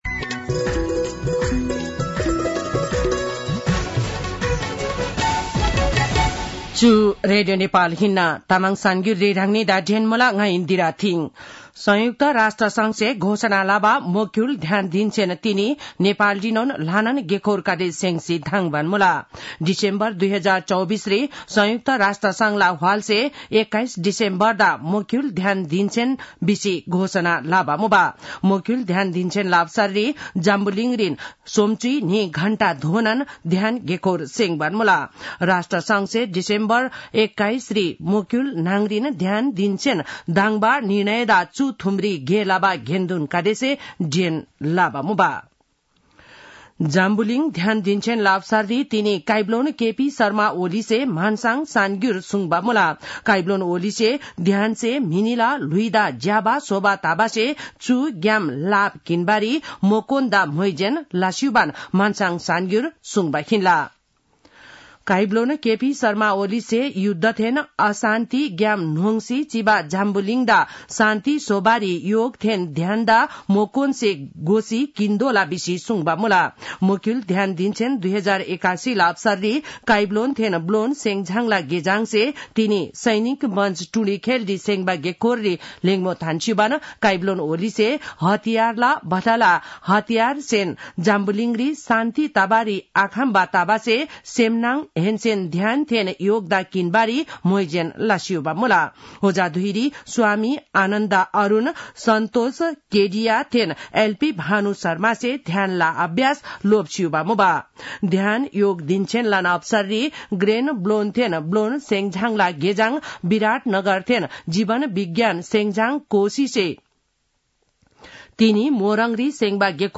तामाङ भाषाको समाचार : ७ पुष , २०८१
Tamang-News-9-6.mp3